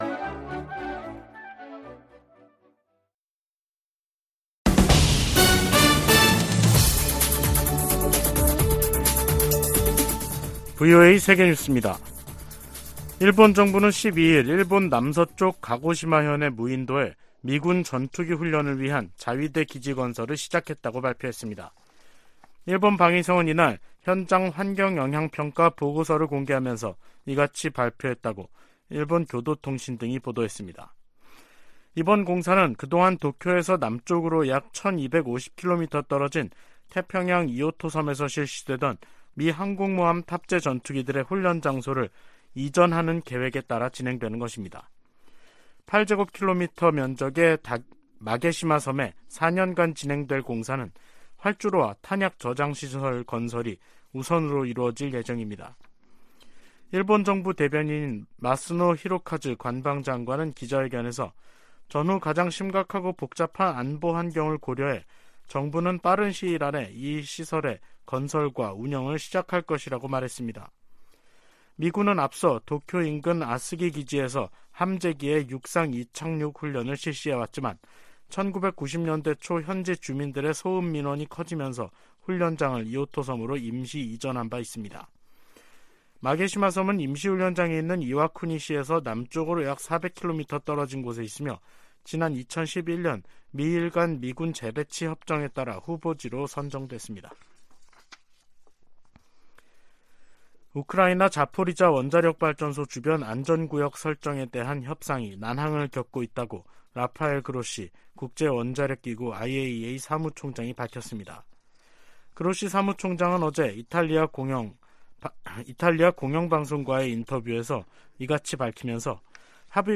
VOA 한국어 간판 뉴스 프로그램 '뉴스 투데이', 2023년 1월 12일 3부 방송입니다. 윤석열 한국 대통령이 핵무기 개발 등 북한의 도발과 안보 위협에 대응한 자체 핵 무장 가능성을 언급했습니다. 미국과 일본의 외교・국방 장관이 워싱턴에서 회담을 갖고 북한의 탄도미사일 도발 등에 대응해 미한일 3자 협력을 강화하기로 거듭 확인했습니다.